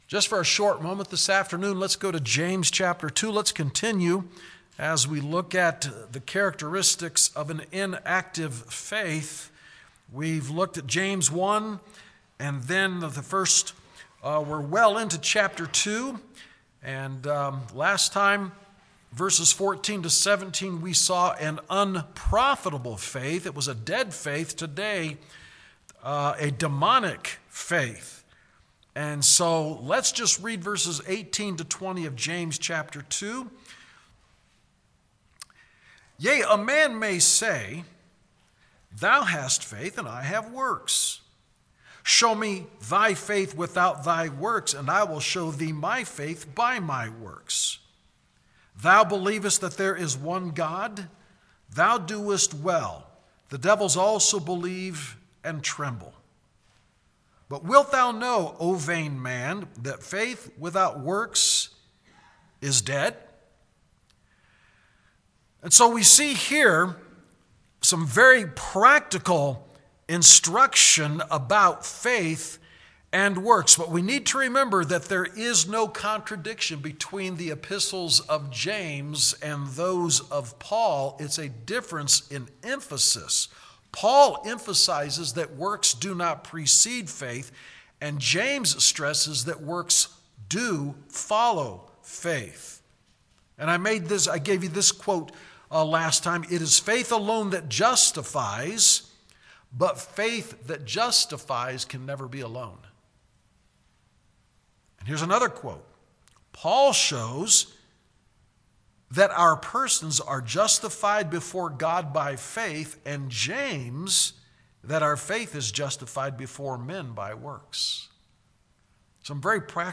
Sermon: A Demonic Faith is Invisible